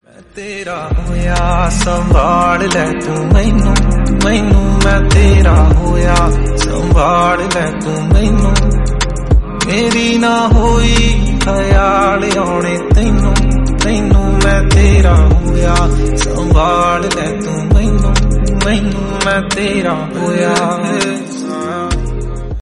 soothing and romantic